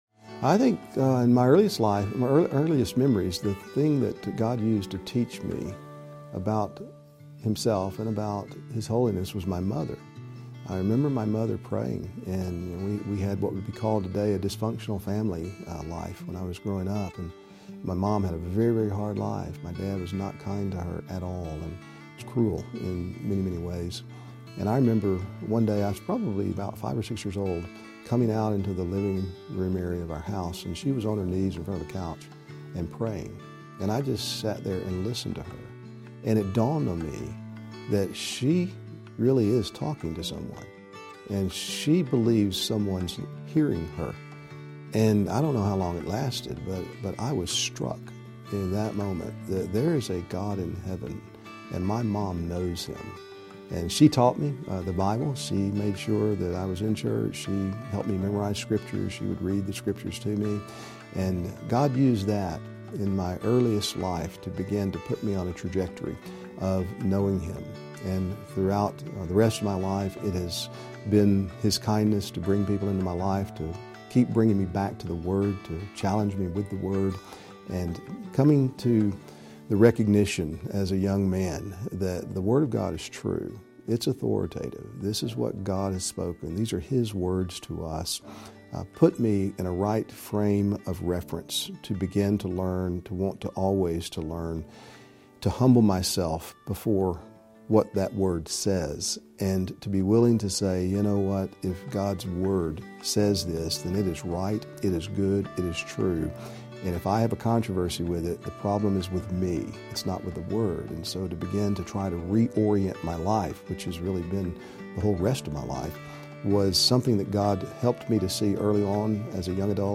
Life Story & Testimony